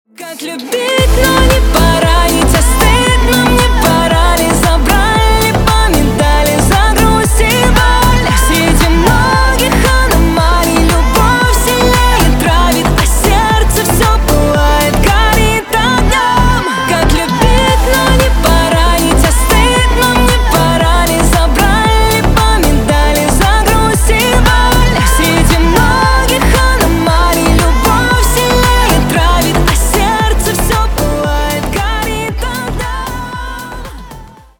поп , танцевальные , красивый женский голос